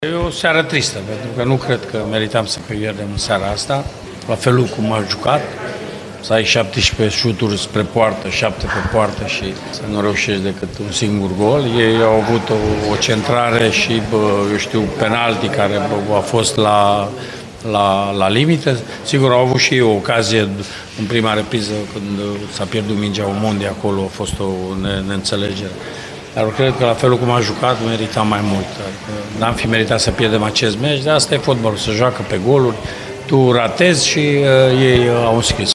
În declarațiile ”la cald”, s-a simțit regretul antrenorului Mircea Rednic că nu au fost concretizate mai multe din șansele, pe care jucătorii lui și le-au creat: